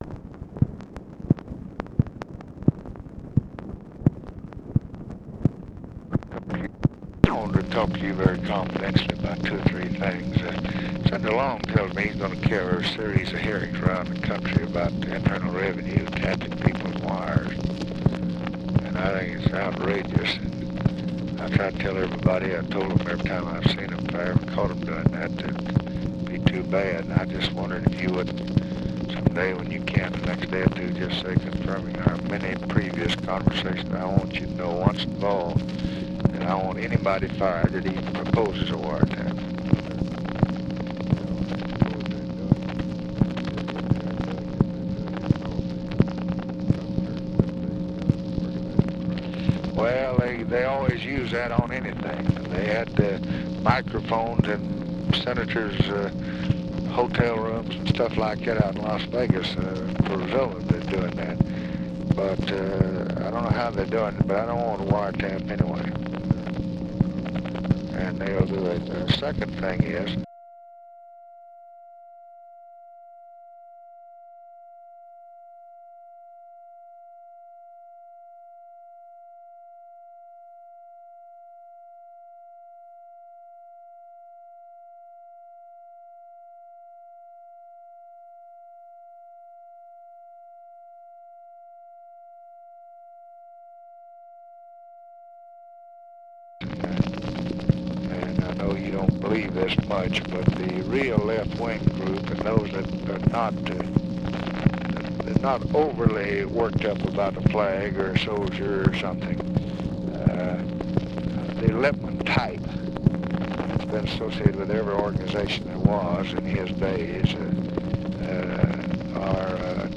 Conversation with ABE FORTAS, June 28, 1965
Secret White House Tapes